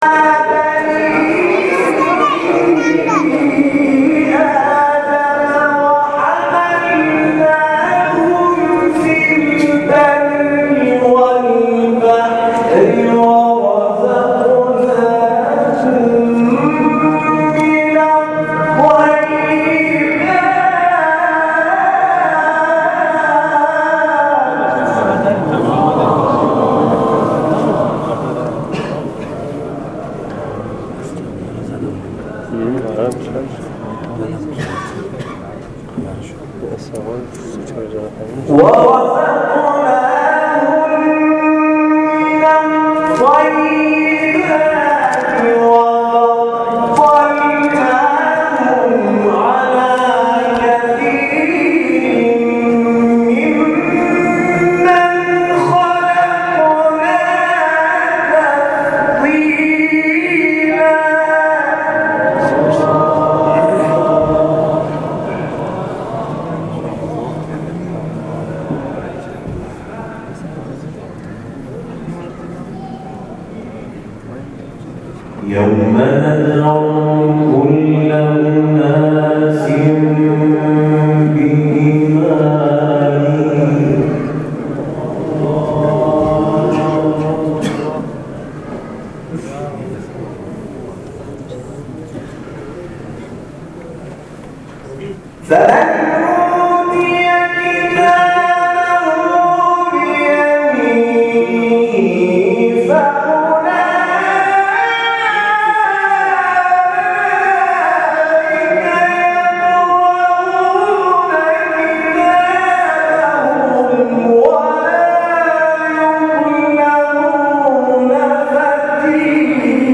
خبرگزاری تسنیم: مرحله نهایی سی‌ویکمین دوره مسابقات بین‌المللی قرآن کریم از دقایقی پیش با رقابت 6 قاری راه یافته به این مرحله آغاز شد و نماینده ایران قرعه خود را تلاوت کرد.
رقابت‌های رشته قرائت بعد از نماز و مغرب و عشاء در سالن اجلاس سران آغاز خواهد شد.